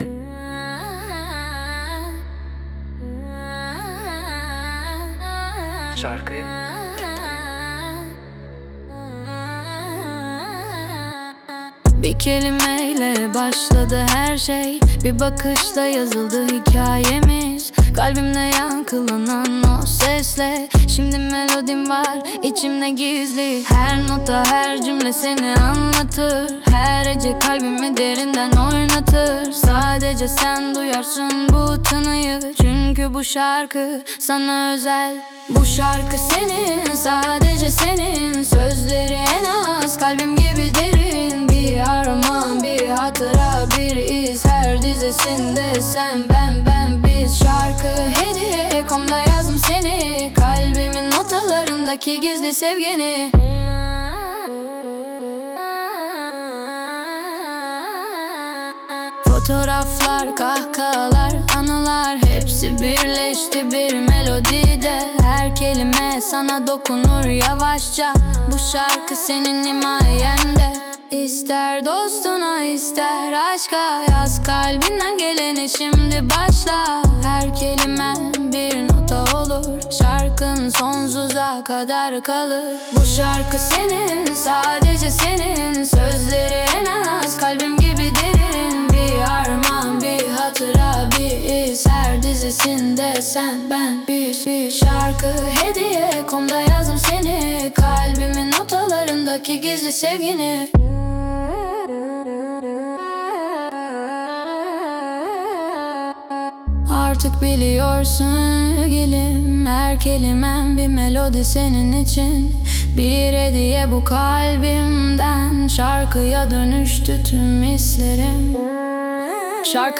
🎤 Vokalli 18.10.2025